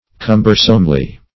cumbersomely - definition of cumbersomely - synonyms, pronunciation, spelling from Free Dictionary
-- Cum"ber*some*ly, adv.